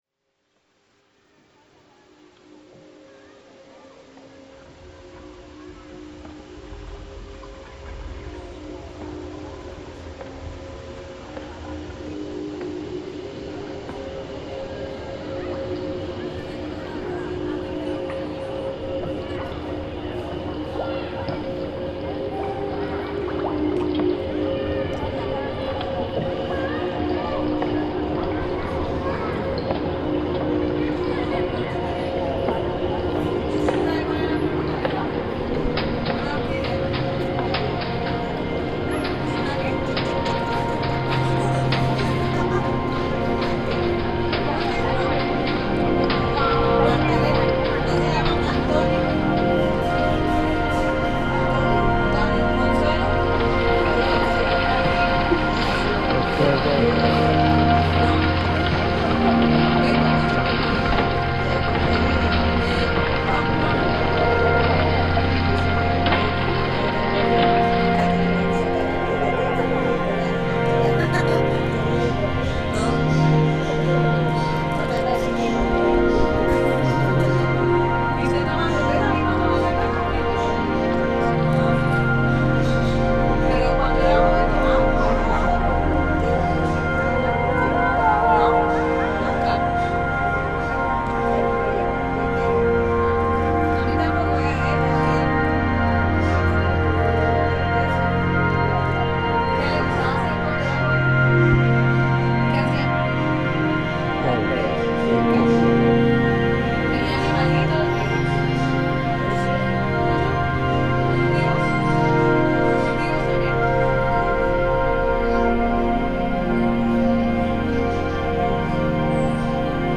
Genre: Ambient/Drone/Field Recordings.